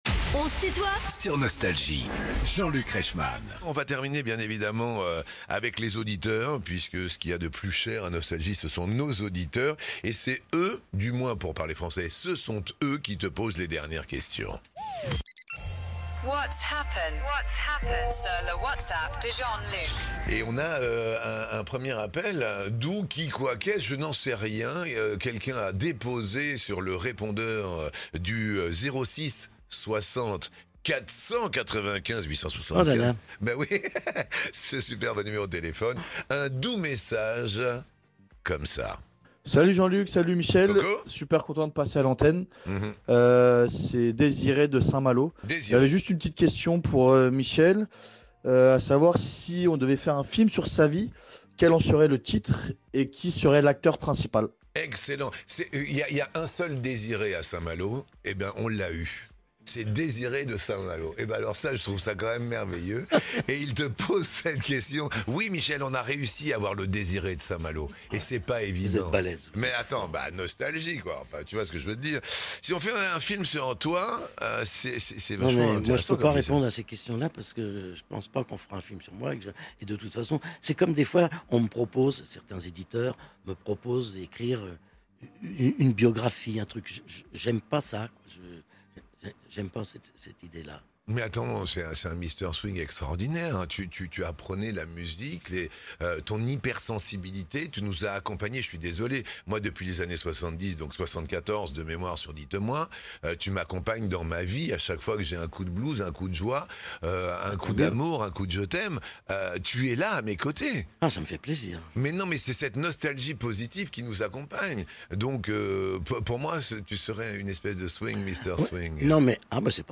Les interviews
Les plus grands artistes sont en interview sur Nostalgie.